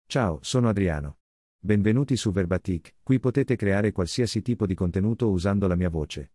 Adriano — Male Italian AI voice
Adriano is a male AI voice for Italian.
Voice sample
Listen to Adriano's male Italian voice.
Adriano delivers clear pronunciation with authentic Italian intonation, making your content sound professionally produced.